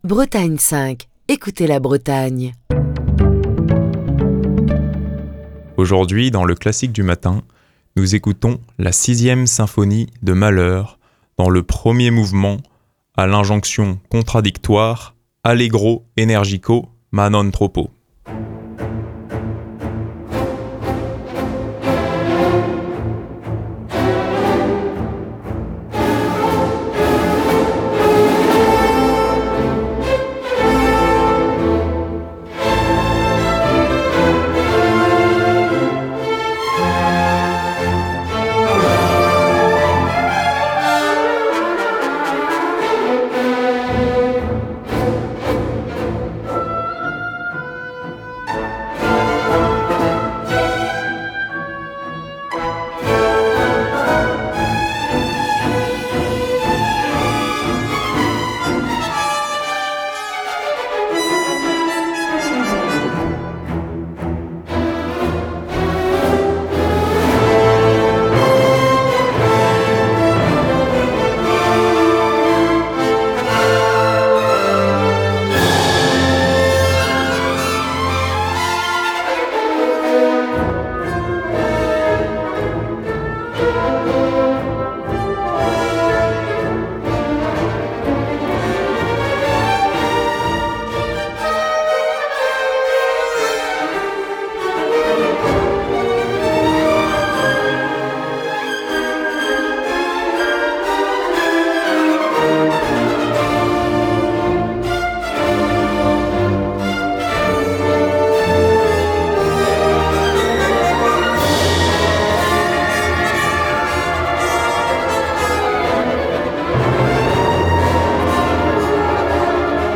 allegro energico, ma non troppo